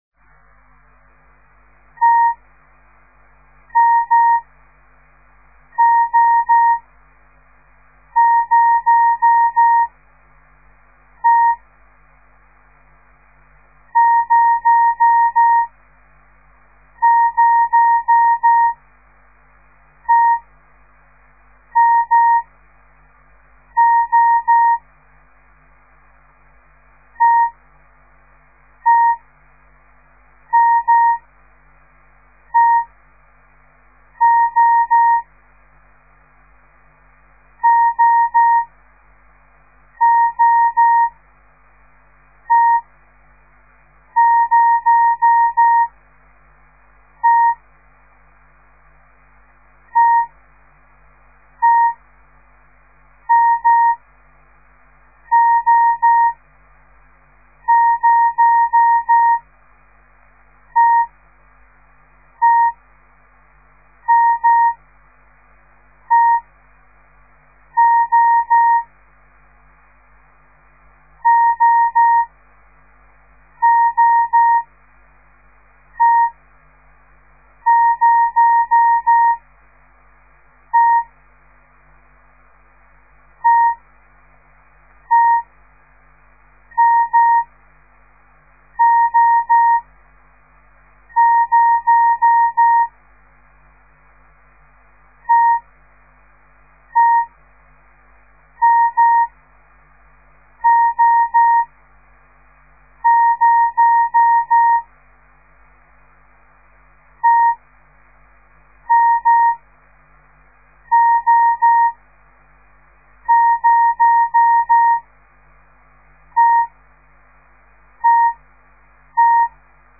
De geluidsfiles bestaan uit geseinde text in letter of cijfergroepen , steeds groepen van 5 tekens en iedere les bestaat uit 25 groepen
De Letter T  dah   /   de letter M da-dah   / De letter O   da-da-dah  /  en het cijfer  0  da-da-da-da-dah